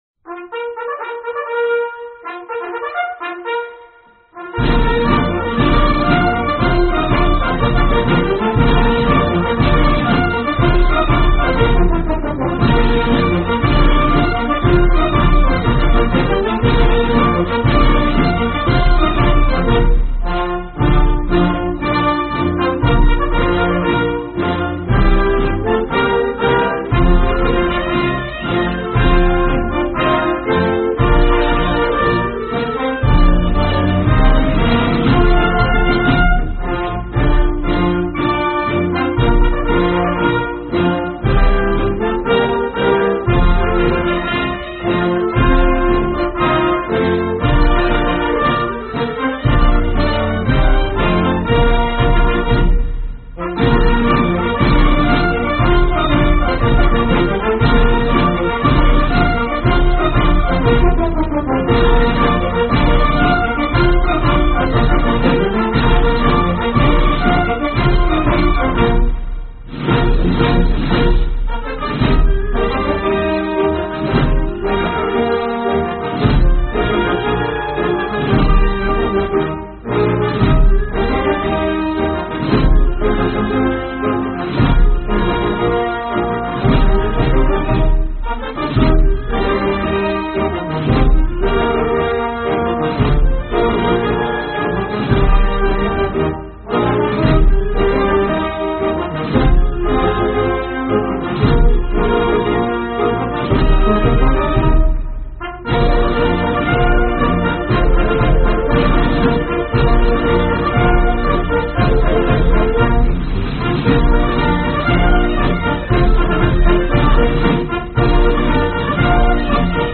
un peu trop "symphonique" peut-être